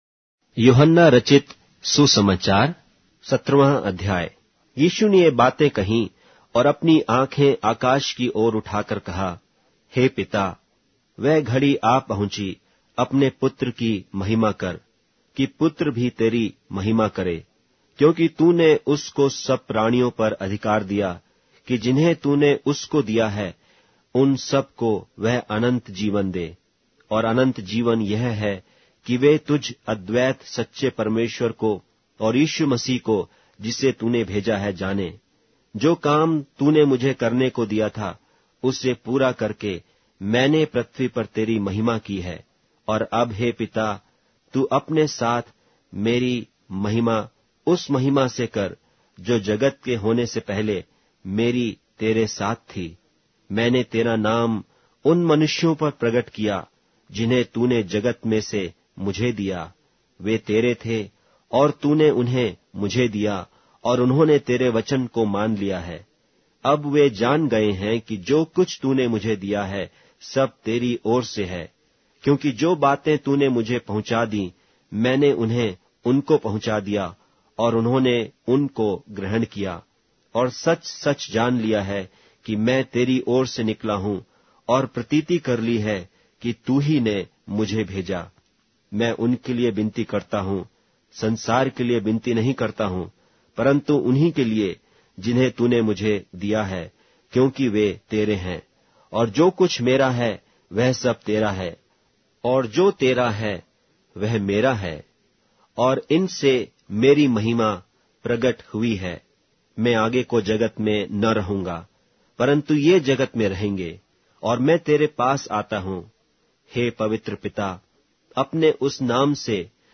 Hindi Audio Bible - John 6 in Hov bible version